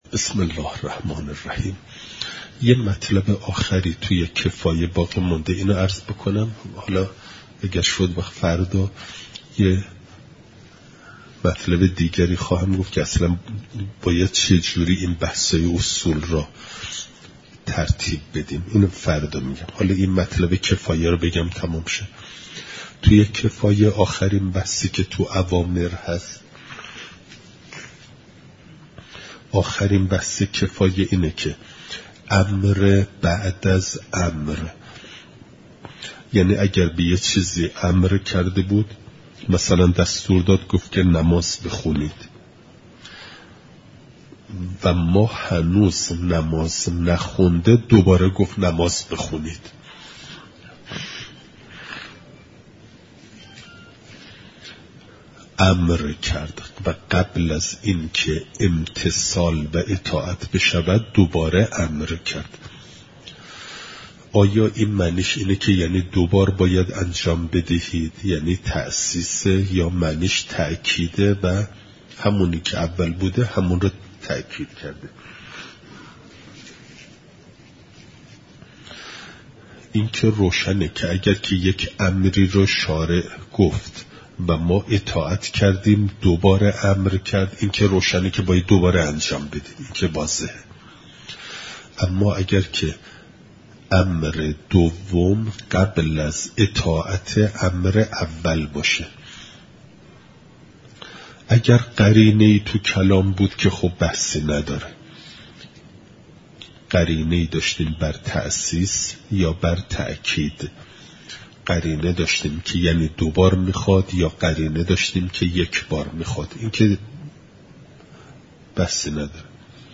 خارج اصول (اجتماع امر و نهی) حرم‌مطهر ۱۳۹۹